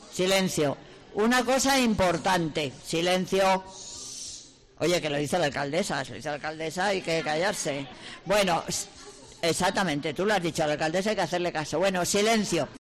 La alcadesa ha ejercido de “cuenta-cuentos” ante una veintena de alumnos del Colegio República de Brasil de San Fermín
Aunque, en algún momento, ha tenido que recurrir a su autoridad de Alcaldesa y pedir silencio ante la algarabía de los pequeños.